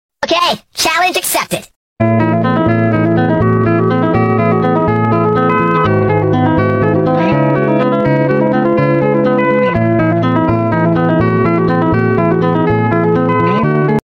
glitch